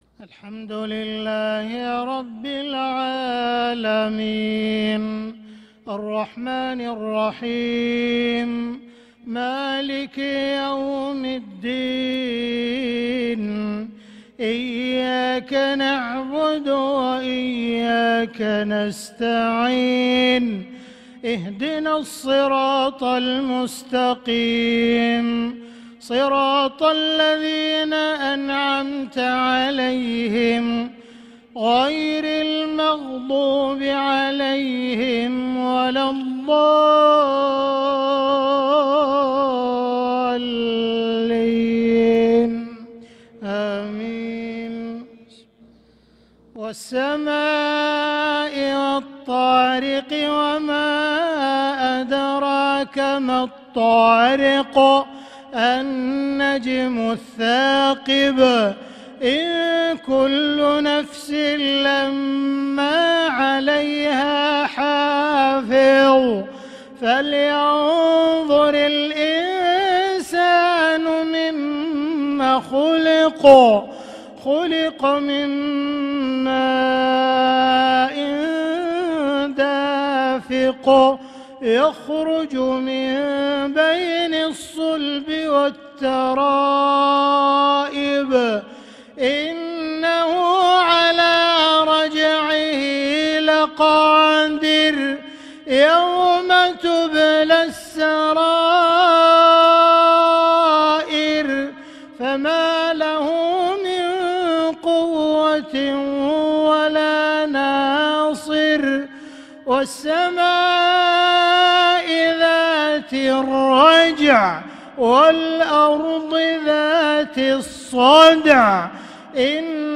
صلاة العشاء للقارئ عبدالرحمن السديس 11 ذو القعدة 1445 هـ
تِلَاوَات الْحَرَمَيْن .